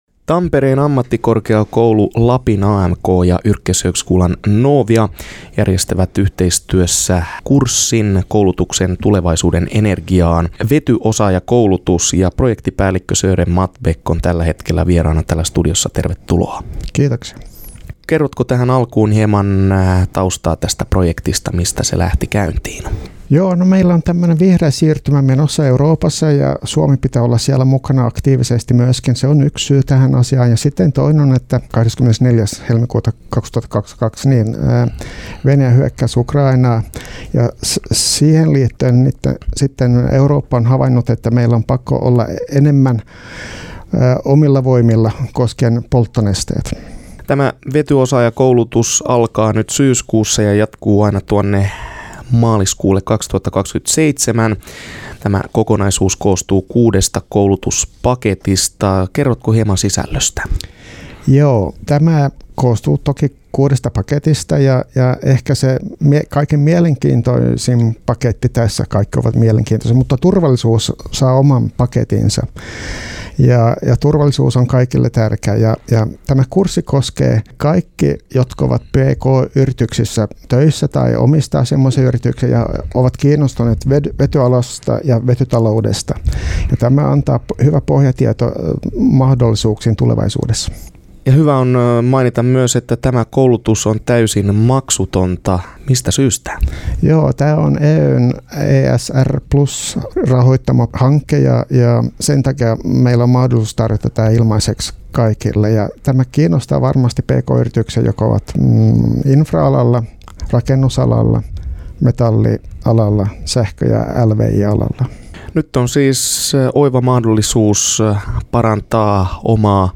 haastattelussa.